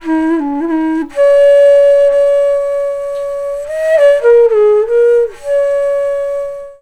FLUTE-B05 -R.wav